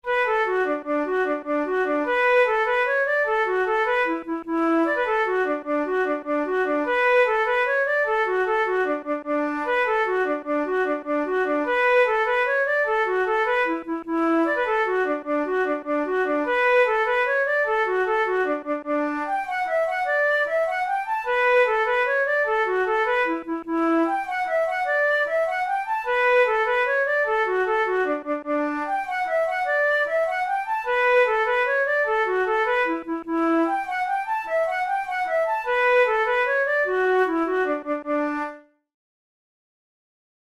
InstrumentationFlute solo
KeyD major
Time signature6/8
Tempo100 BPM
Jigs, Traditional/Folk
Traditional Scottish jig